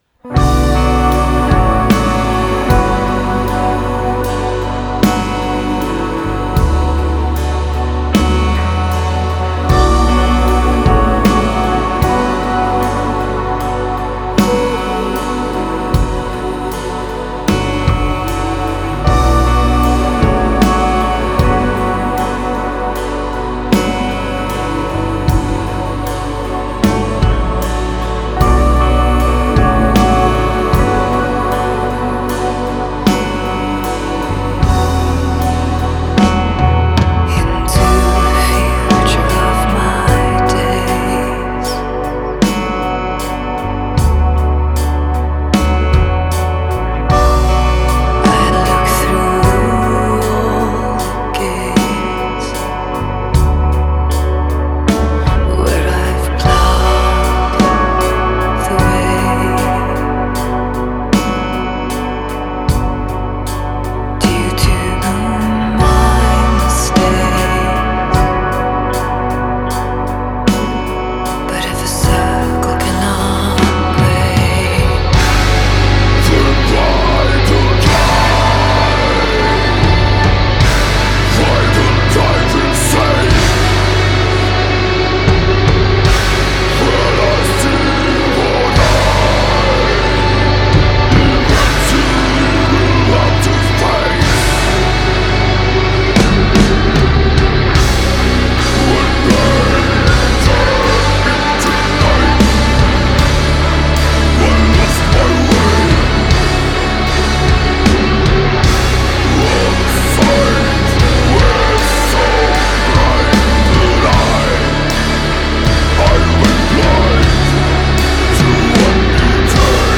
Genre : Atmospheric Doom/Death